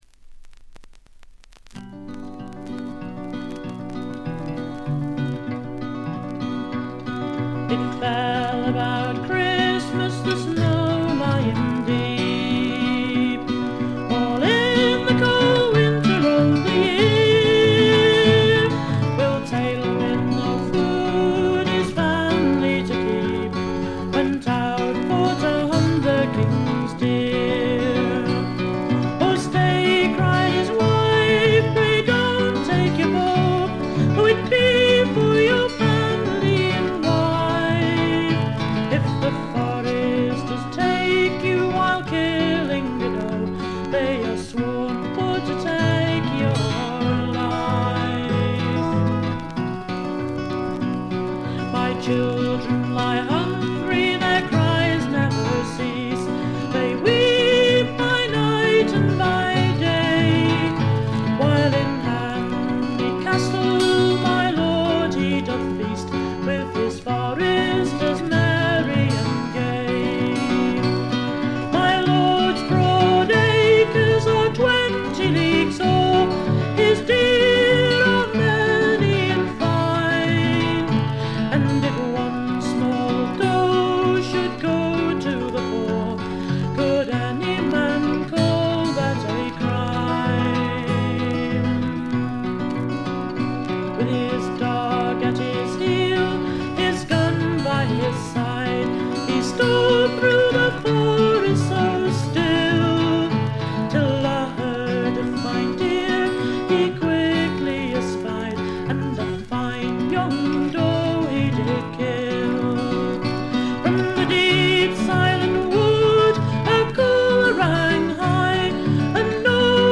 バックグラウンドノイズやや多め大きめですが、鑑賞を妨げるほどのノイズはありません。
試聴曲は現品からの取り込み音源です。